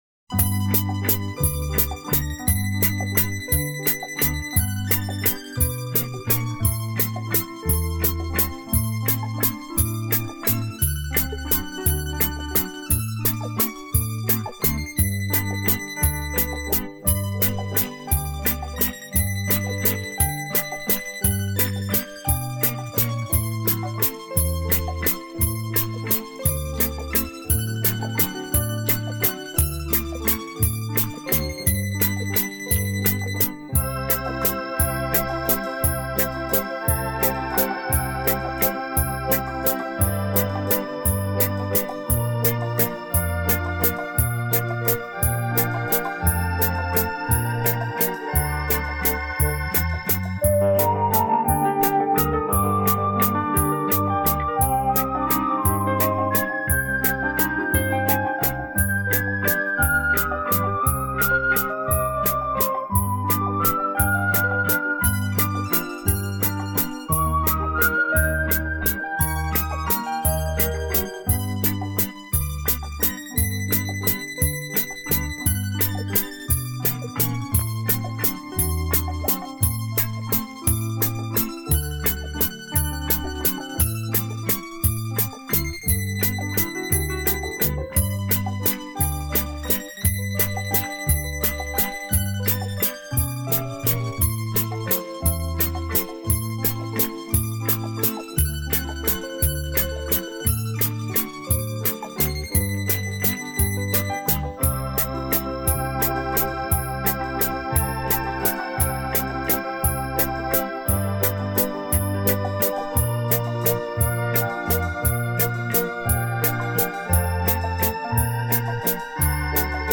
电影主题音乐